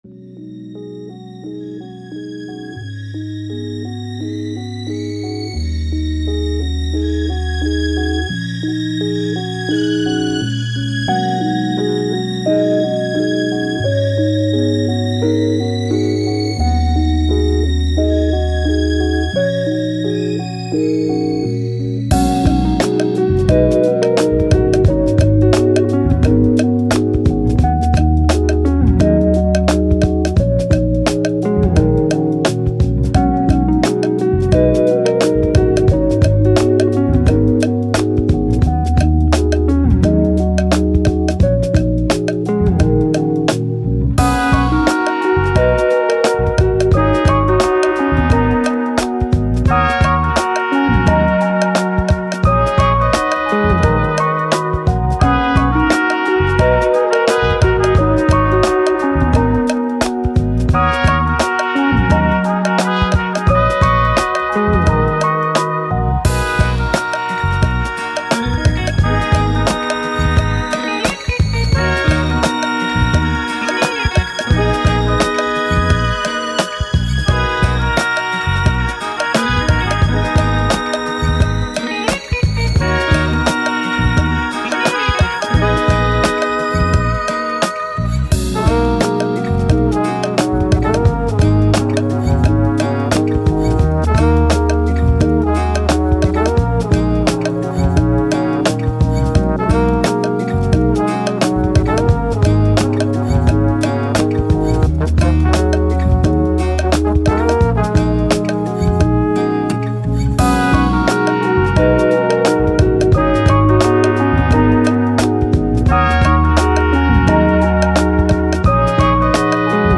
Downtempo, Lofi, Emotive, Thoughtful